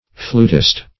Flutist \Flut"ist\, n. [Cf. F. fl[^u]tiste.]